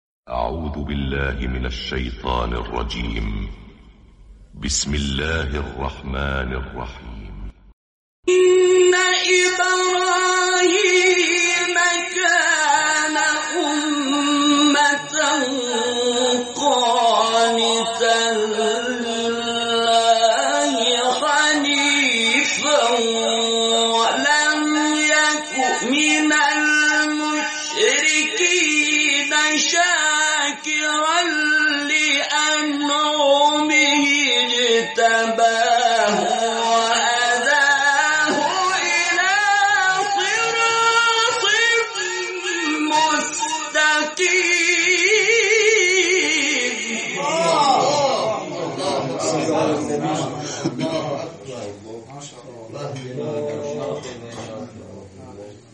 گروه شبکه اجتماعی: نغمات صوتی از تلاوت‌های قاریان به‌نام کشور را می‌شنوید.